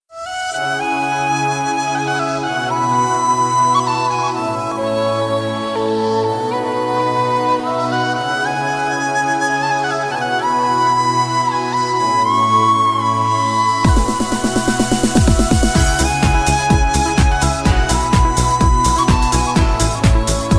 Música Clasica